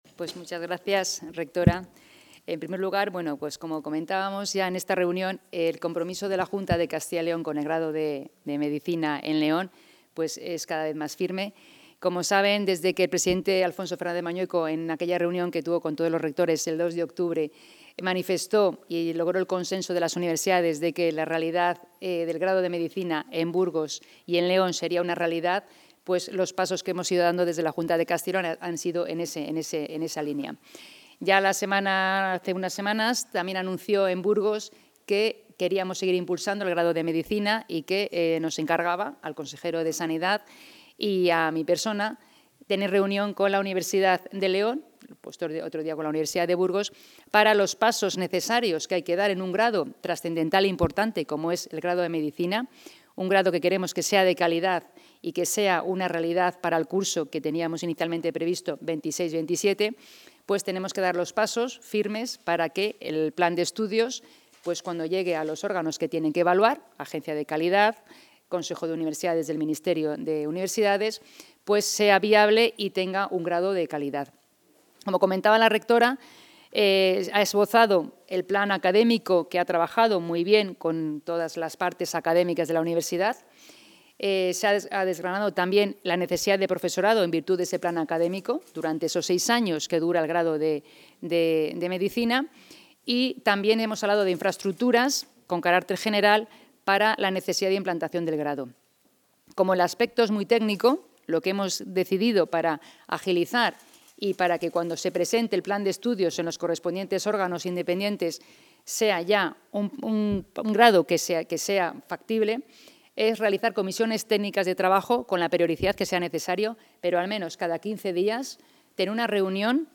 Intervención de la consejera de Educación.